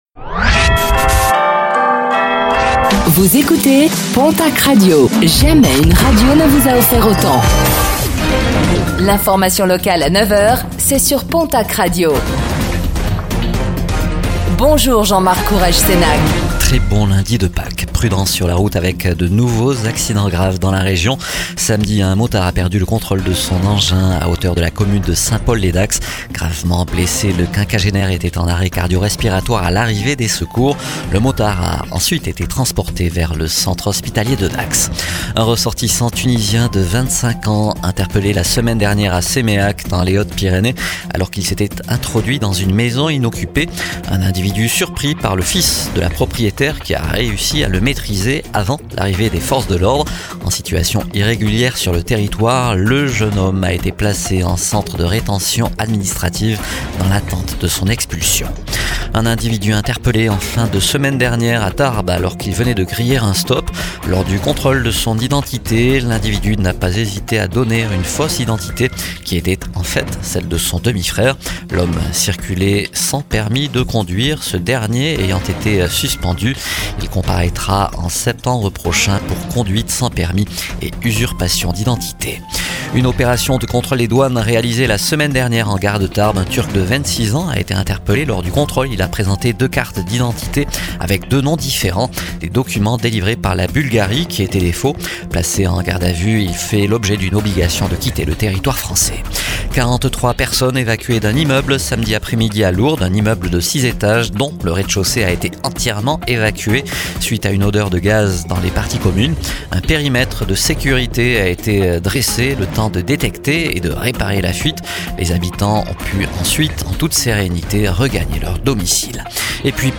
Réécoutez le flash d'information locale de ce lundi 06 avril 2026